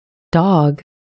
英语发音api.可以把音频保存到本地目录，如果没有再从网上下载。